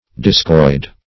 Discoid \Dis"coid\, n.